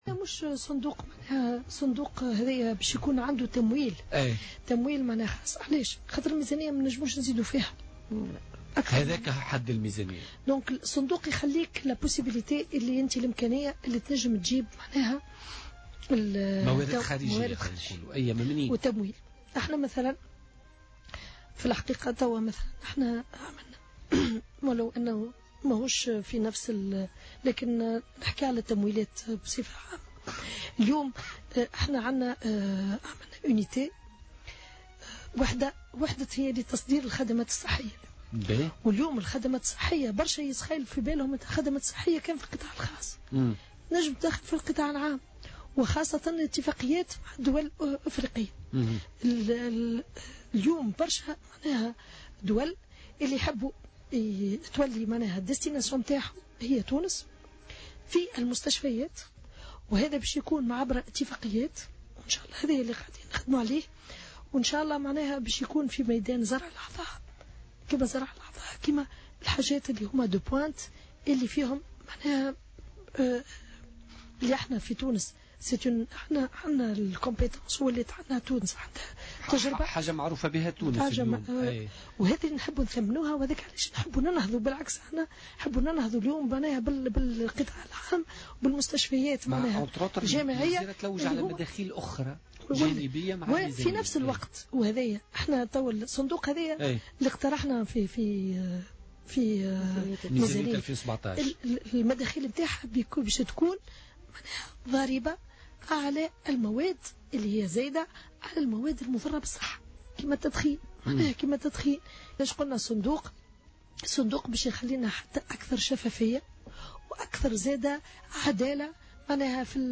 و أضافت مرعي ضيفة برنامج "بوليتيكا" اليوم أن موارد هذا الصندوق ستكون متأتية من الضرائب المفروضة على المواد المضرة بالصحة على غرار التدخين.